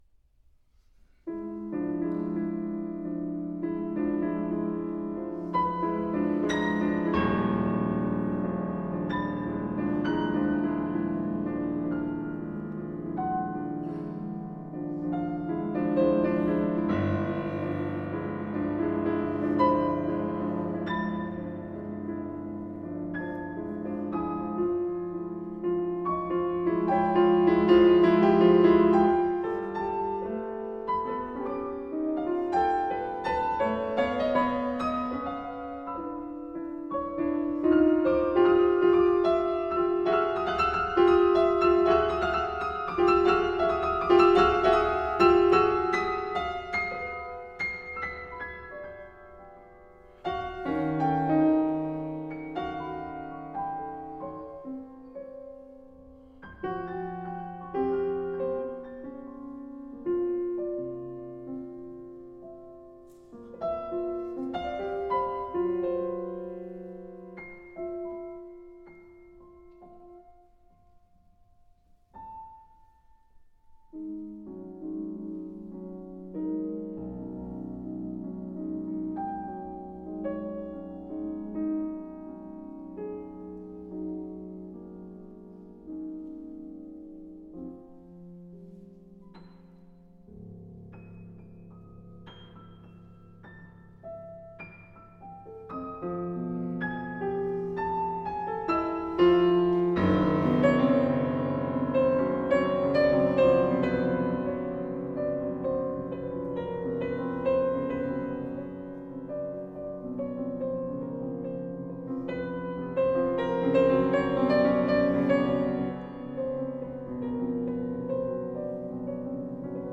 Swirling treble ostinato
A fantastic live performance